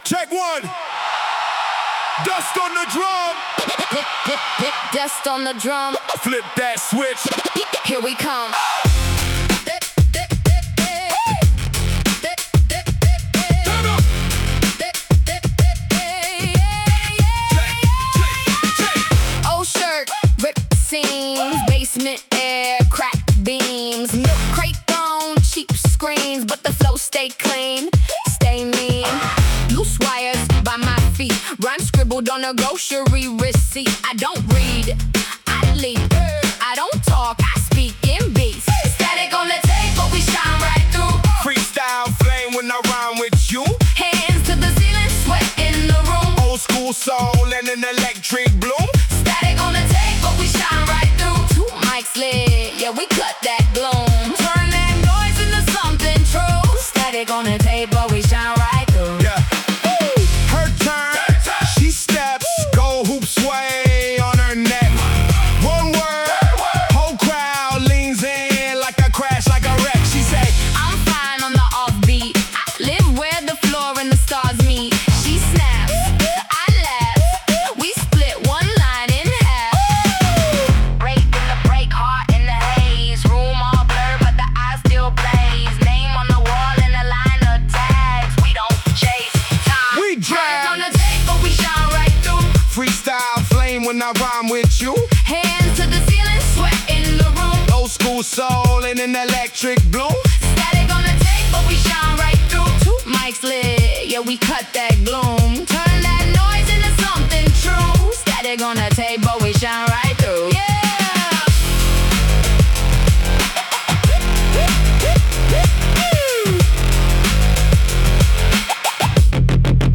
electro hip hop